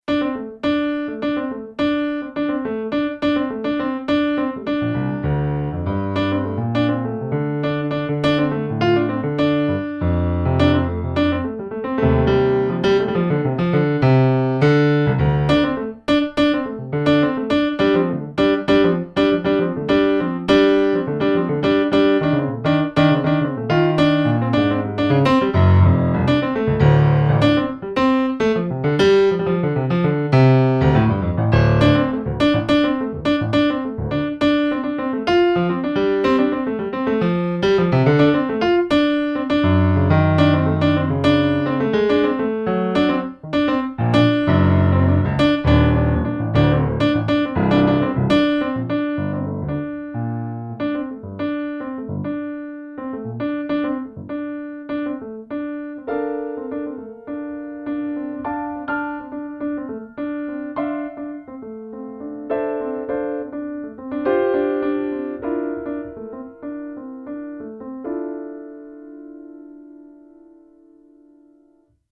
Das aggressive Ding verliert sich in Hilflosigkeit zum Schluss.
Sixty Seconds Sounds 14 is a somewhat agitated piece of piano music with lots of staccato. The aggressive thing gets lost in helplessness at the end.